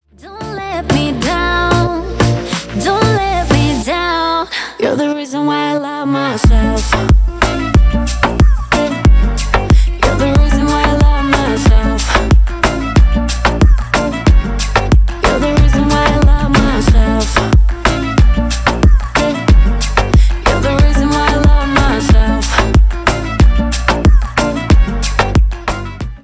клубные
поп